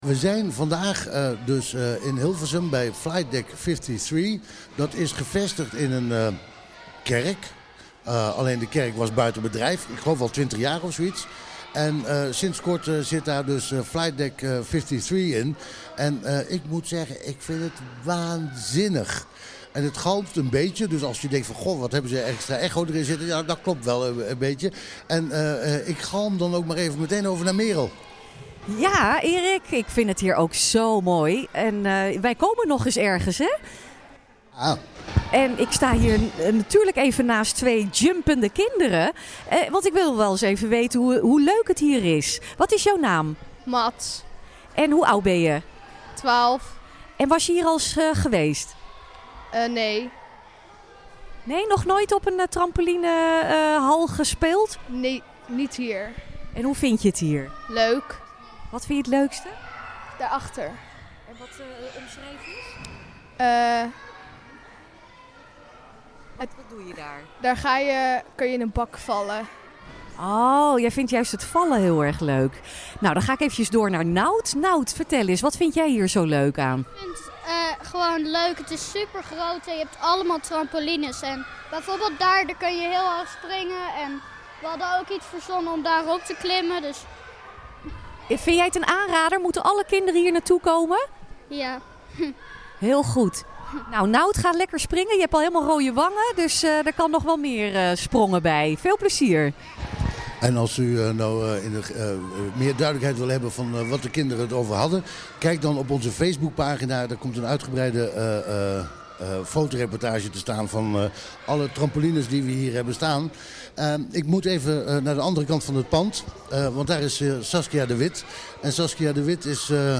Deze week kwam Achter 't Net vanuit de 'kerk' van Flight Deck 53.